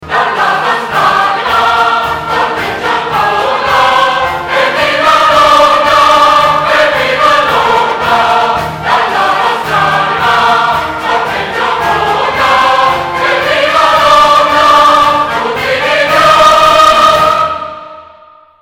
suoneria per smartphone